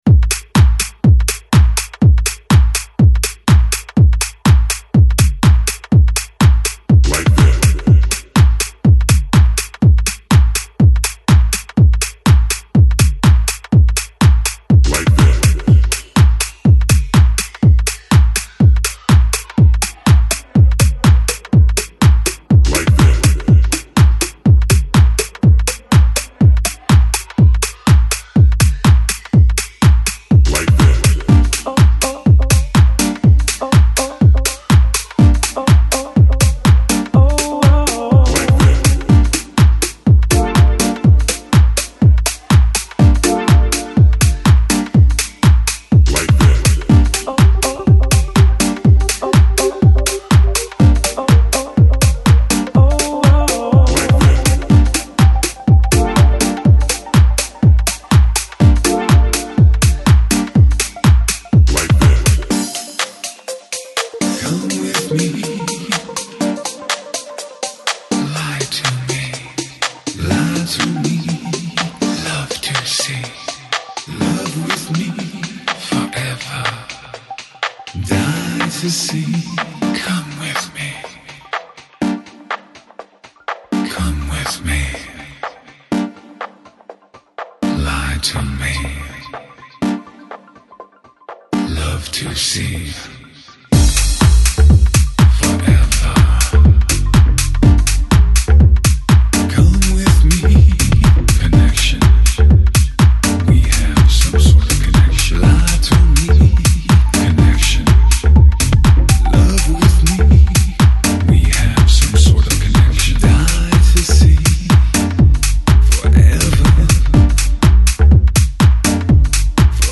House, Deep House, Tech House Год издания